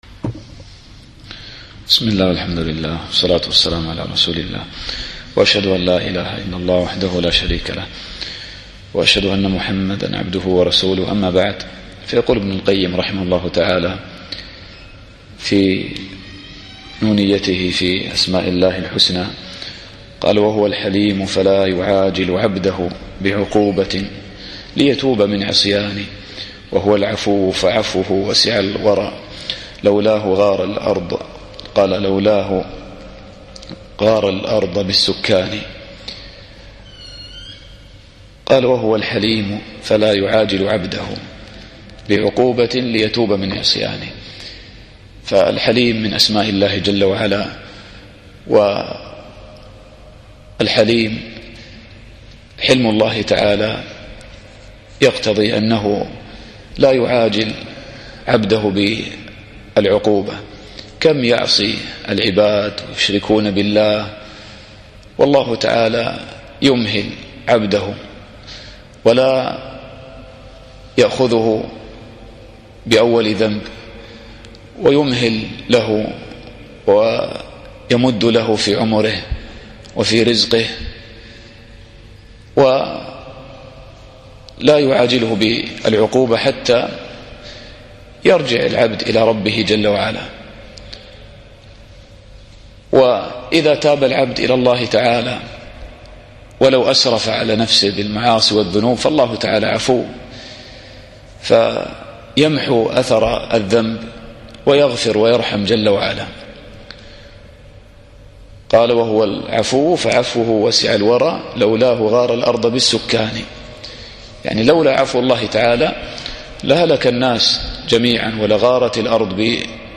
الدرس الثالث والعشرون